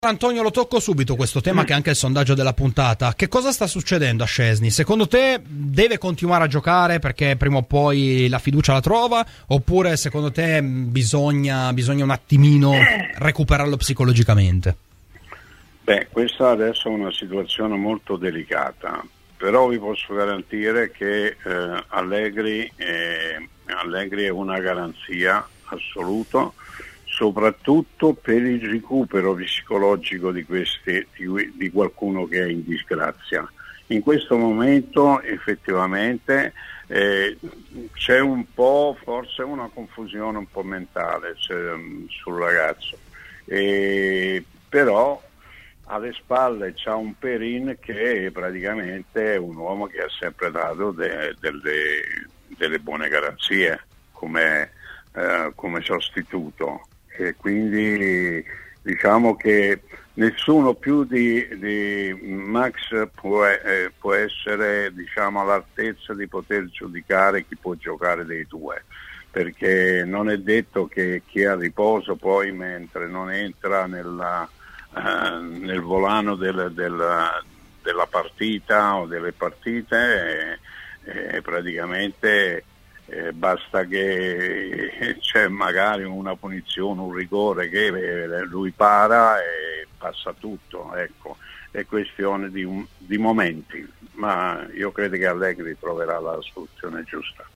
"Antonio Caliendo" ospite a Fuori Di Juve.
© registrazione di Radio Bianconera